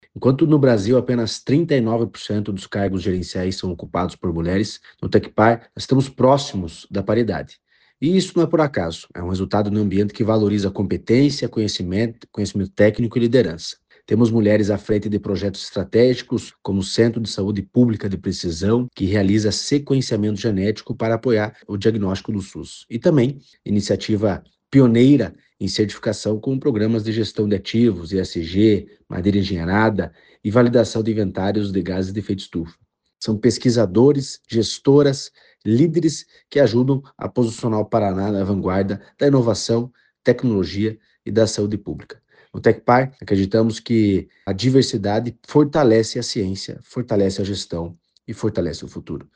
Sonora do diretor-presidente do Tecpar, Eduardo Marafon, sobre a participação das mulheres nos avanços do Instituto